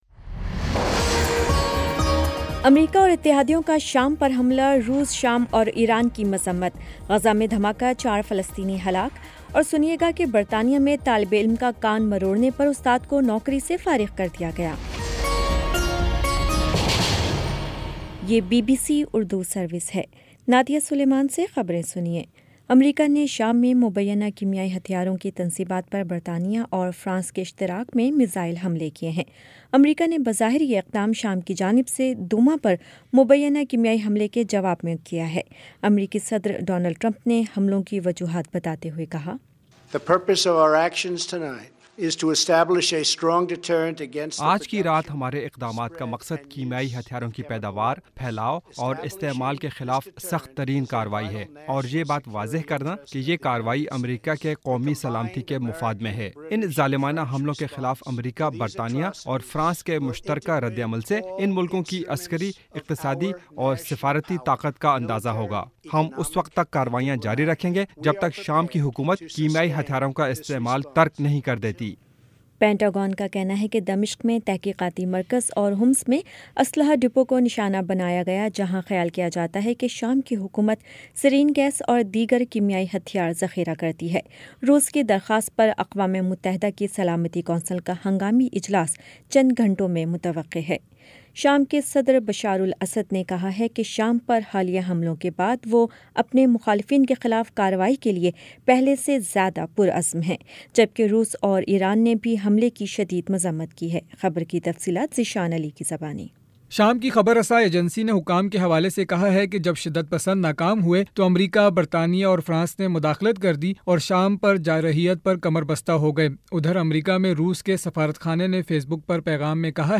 اپریل 14 : شام سات بجے کا نیوز بُلیٹن
دس منٹ کا نیوز بُلیٹن روزانہ پاکستانی وقت کے مطابق شام 5 بجے، 6 بجے اور پھر 7 بجے۔